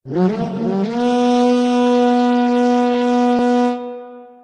HornSmall.ogg